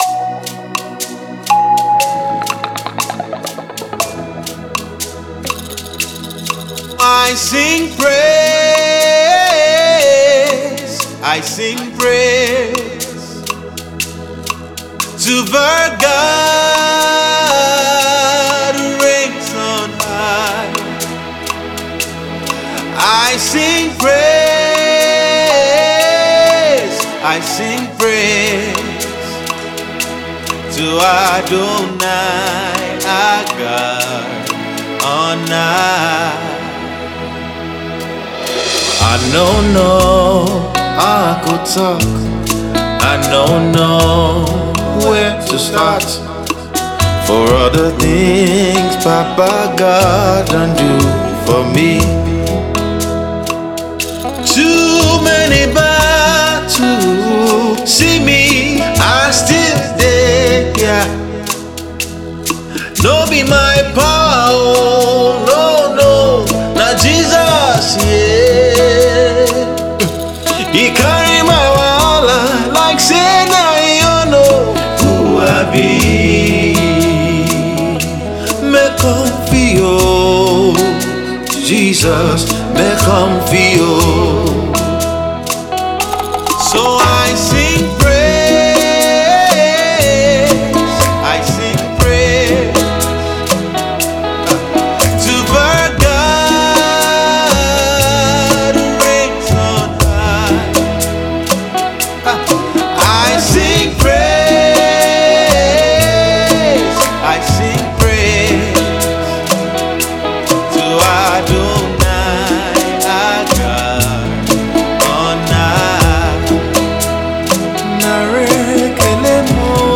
powerful song of Thanksgiving
gospel